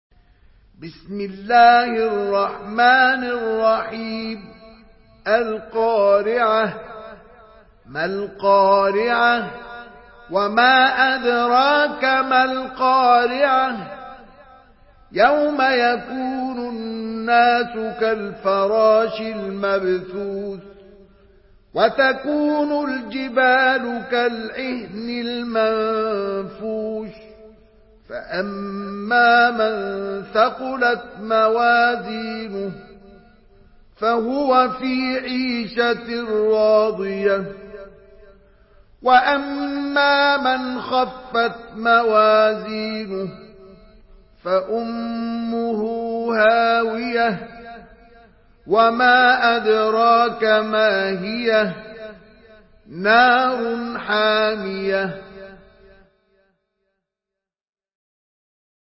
Surah Al-Qariah MP3 in the Voice of Mustafa Ismail in Hafs Narration
Murattal